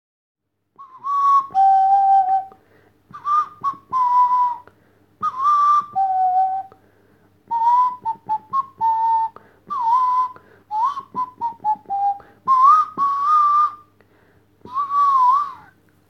Inklusive selbst gespieltem Nasenflötensolo.
Dabei gibt es vermutlich nur ein Instrument, das schlimmer klingt als eine Nasenflöte, und das sind 100 Blockflöten, von 100 Grundschülern gleichzeitig gespielt.